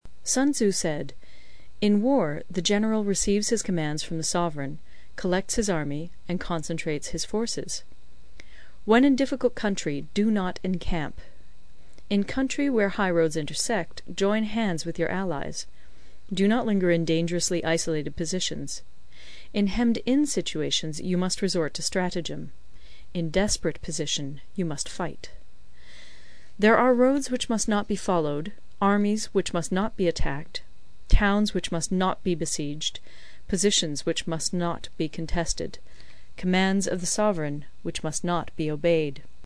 有声读物《孙子兵法》第45期:第八章 九变(1) 听力文件下载—在线英语听力室